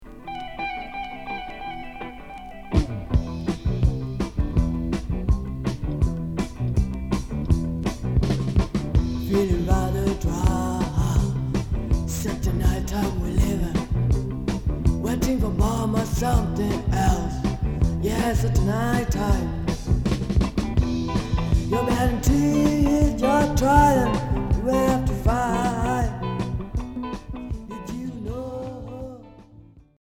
Thaï rock Unique 45t retour à l'accueil